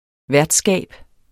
Udtale [ ˈvæɐ̯dˌsgæˀb ]